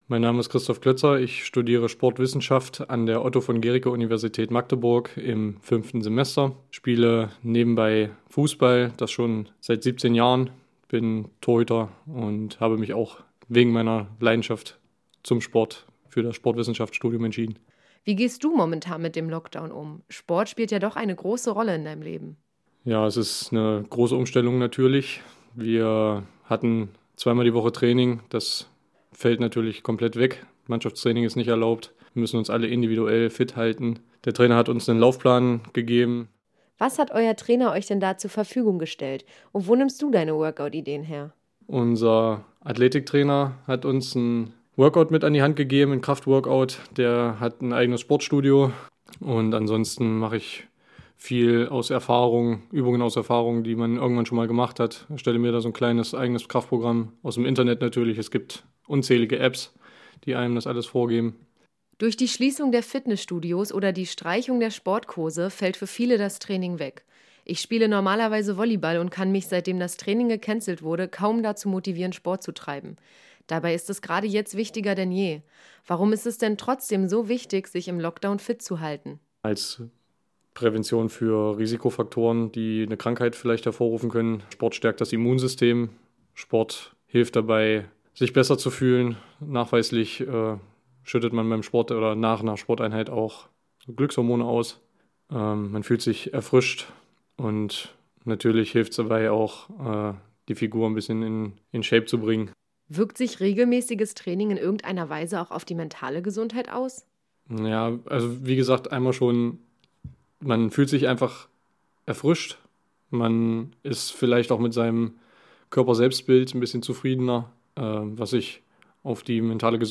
Interview mit einem Sportstudenten – h²radio
Interview_mit_einem_Sportstudenten.mp3